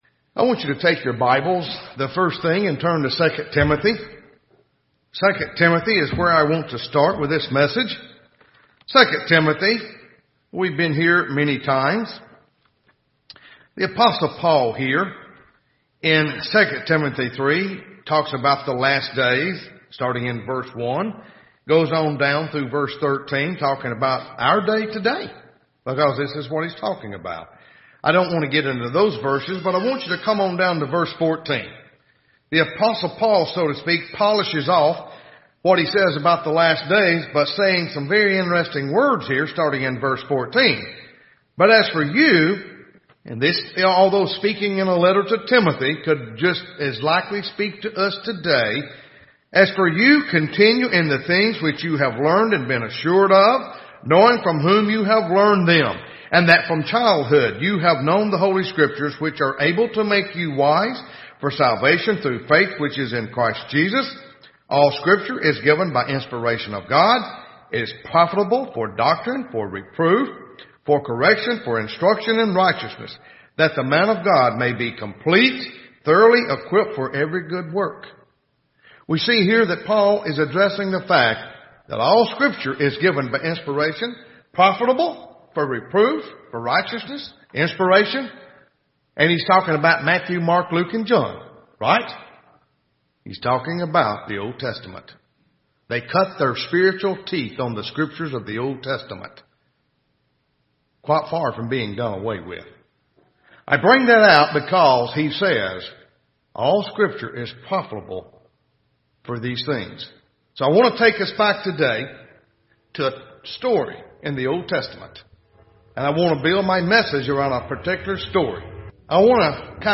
This sermon draws seven lessons from the story of Jonah, the reluctant prophet.